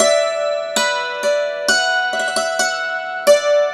Dulcimer17_128_G.wav